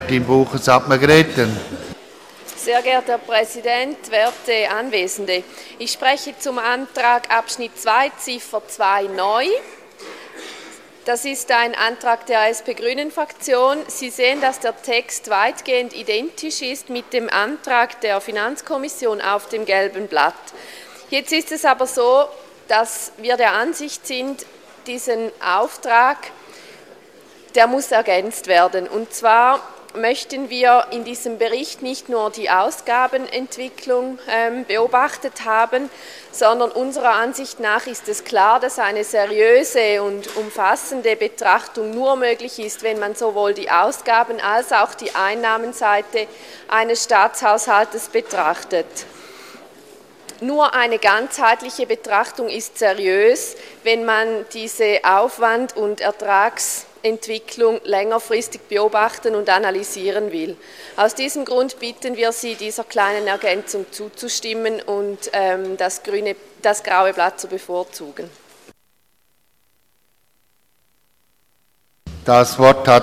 24.2.2015Wortmeldung